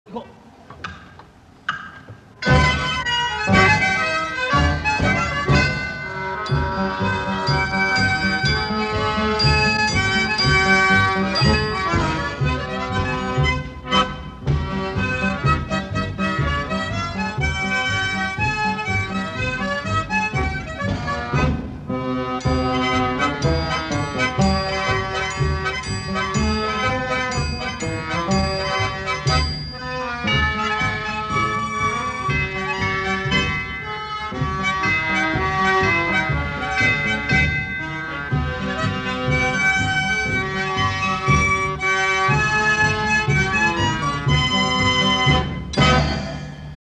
Blue Bird Band
National Sanatorium Nagashima Aiseien, Okayama